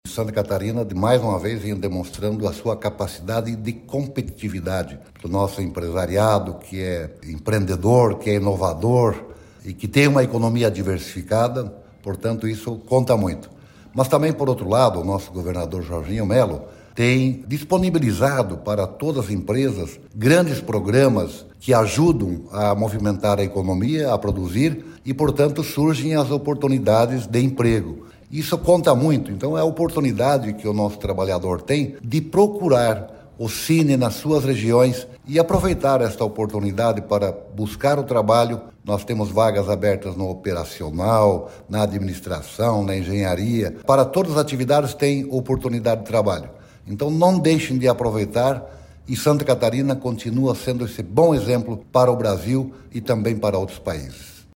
Para o secretário de Estado de Indústria, Comércio e Serviço, Silvio Dreveck, no estado há grandes programas que ajudam a movimentar a economia e, portanto surgem as oportunidades de emprego:
SECOM-Sonora-secretario-da-SICOS-26.mp3